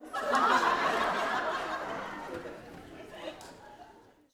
Audience Laughing-06.wav